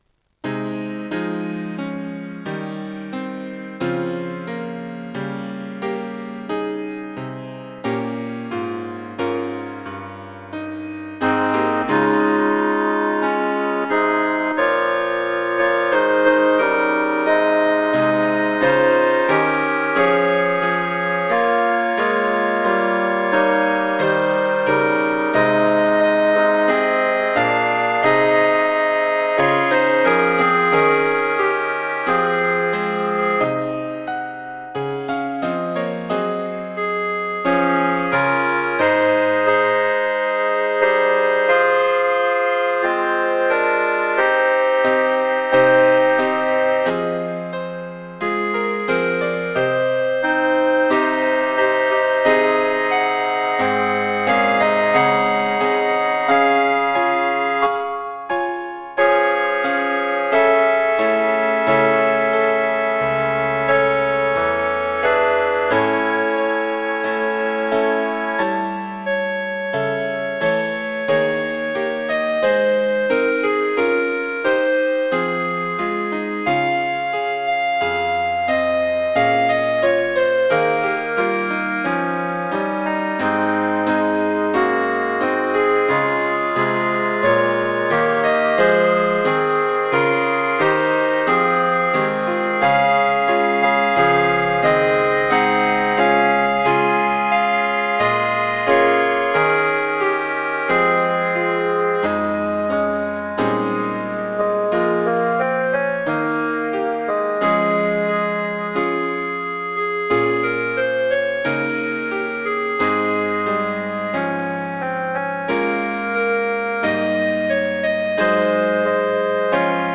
N.B. Het zijn thuisopnames, dus verwacht geen uitgebalanceerde opname!
Meestal is van de liedjes alleen het 1e couplet ingezongen
Koor Muziek(mp3) Muziek(PDF)